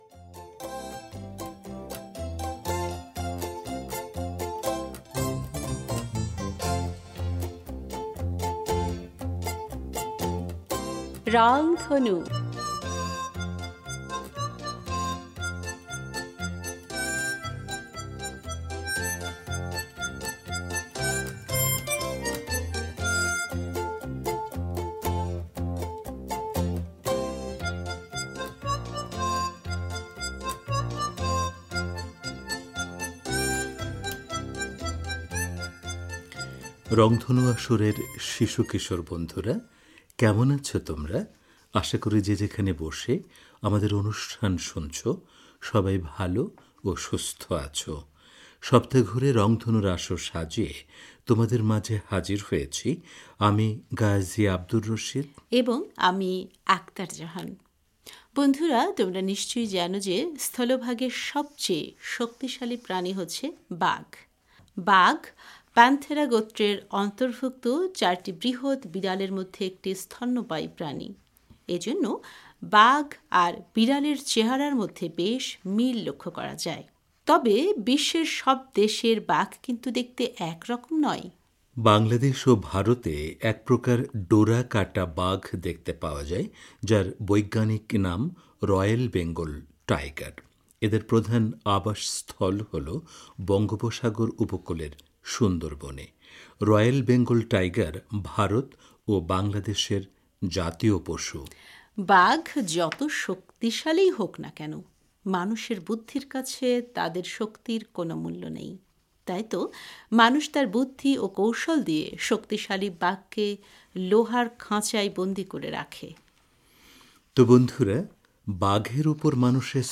তো বন্ধুরা,, বাঘের ওপর মানুষের শ্রেষ্ঠত্ব সম্পর্কে আজকের আসরে আমরা একটি গল্প শোনাব। আর গল্প শেষে থাকবে একটি গান।